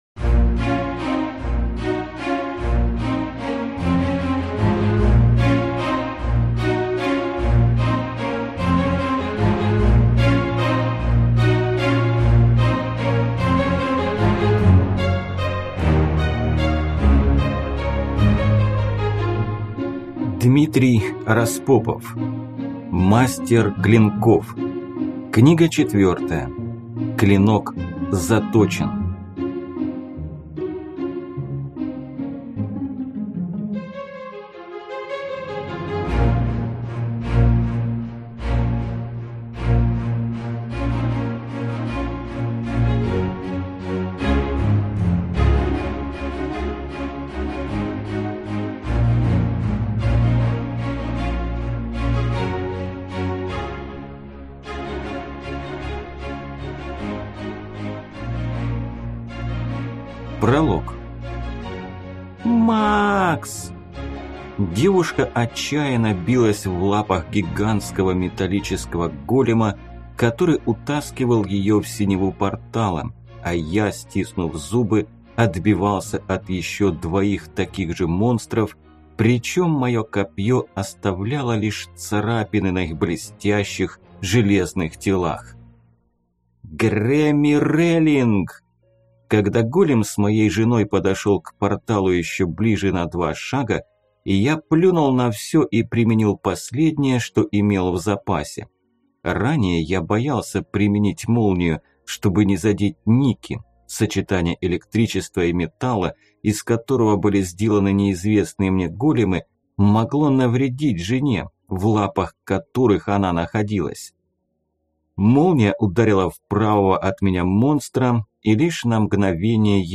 Аудиокнига Мастер клинков. Клинок заточен - купить, скачать и слушать онлайн | КнигоПоиск